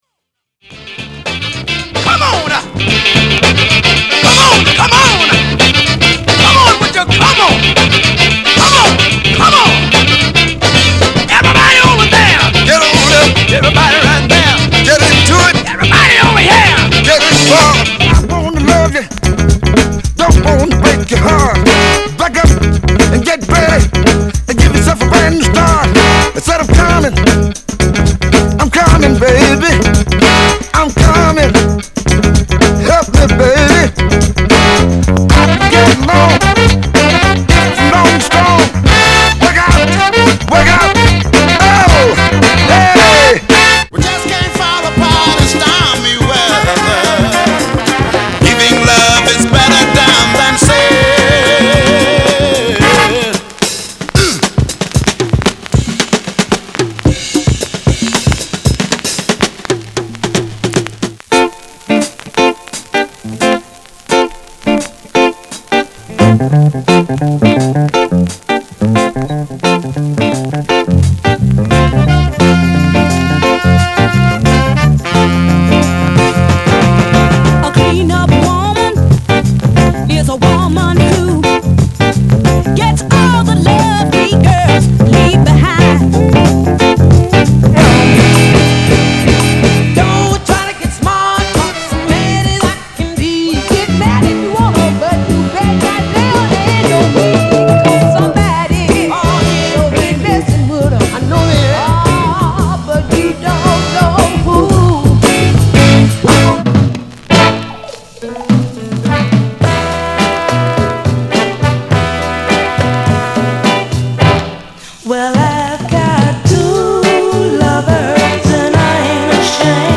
R&B、ソウル